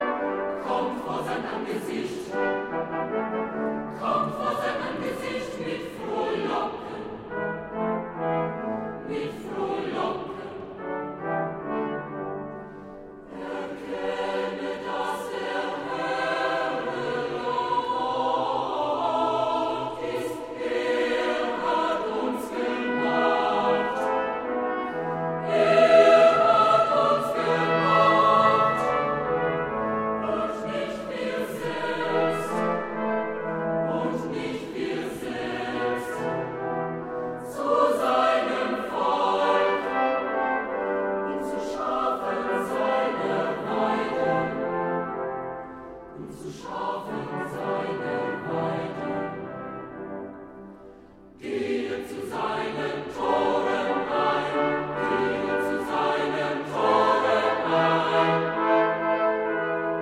• Sachgebiet: Klassik: Chormusik